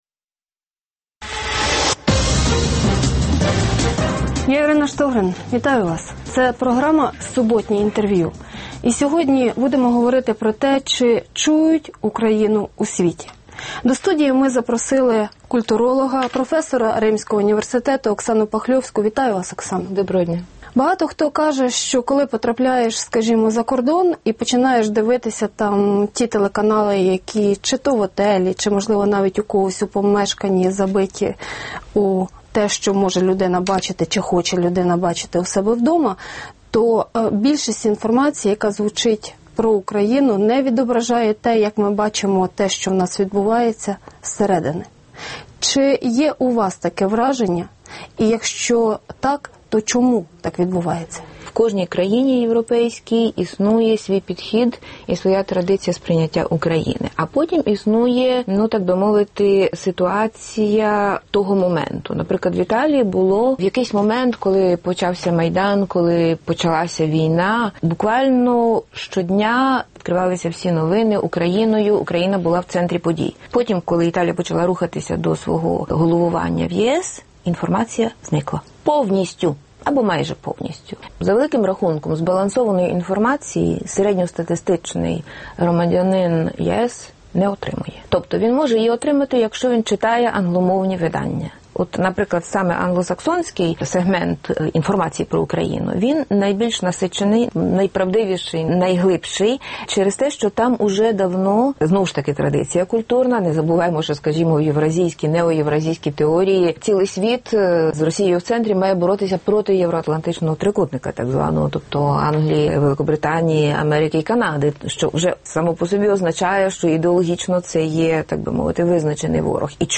Суботнє інтерв’ю
Гість відповідає, в першу чергу, на запитання друзів Радіо Свобода у Фейсбуці